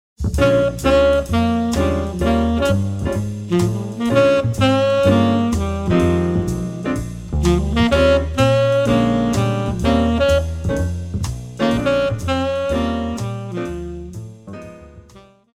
Jazz
Band
Etudes
Blues,Funk
Only backing